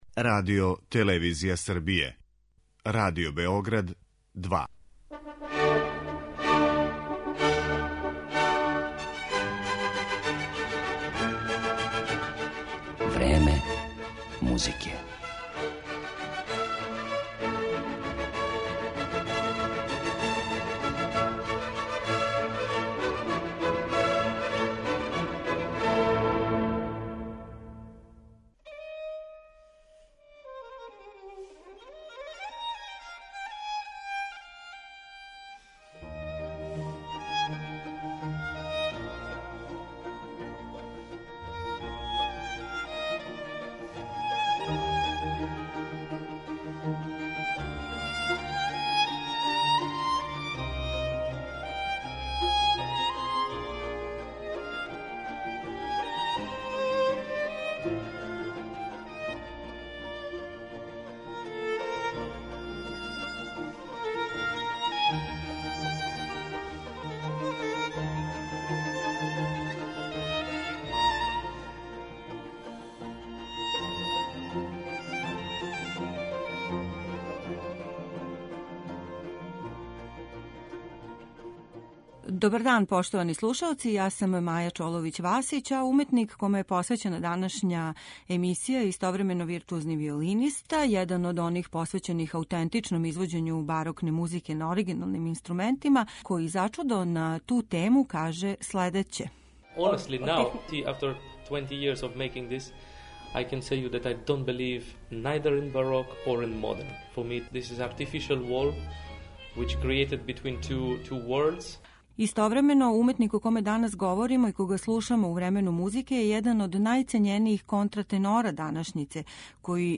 Дмитриј Синковски је виртуозни виолиниста и један од најцењенијих контратенора данашњице, чије су узбудљиве и оригиналне интепретације освојиле публику широм света.
Упознали смо га 2019. године на концерту у оквиру БЕМУС-а, када је са ансамблом La Voce Strumentale извео „Четири годишња доба" Антонија Вивалдија, као и једну његову кантату. Управо избором из овог програма, као и музиком Јохана Себастијана Баха представићемо овог изузетног уметника, који је дан пре концерта дао и ексклузивни интервју за Радио Београд 2.